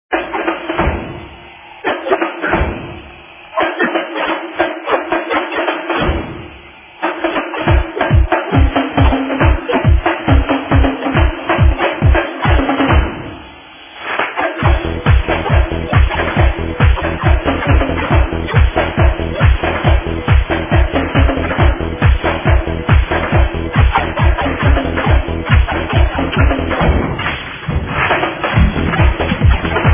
We call it the Bongo Song coz of the percussion through it.